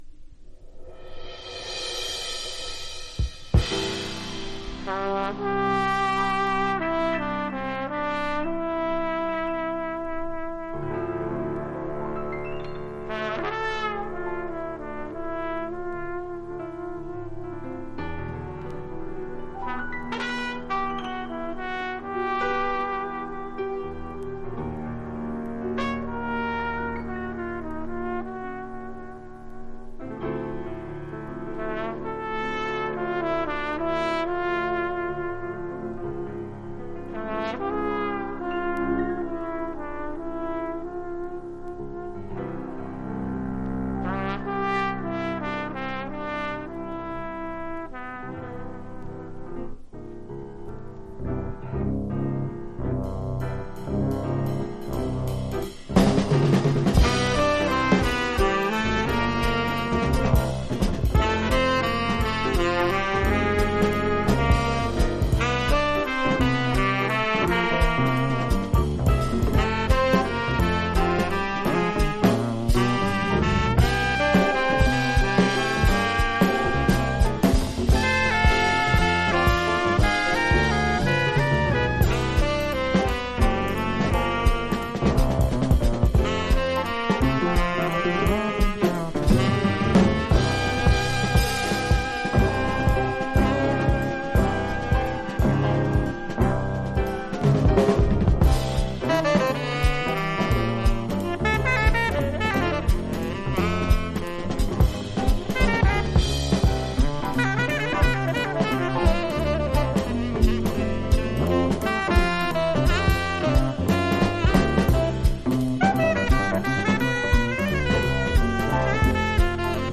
（A-3 小傷によりチリ、プチ音あり）
Genre US JAZZ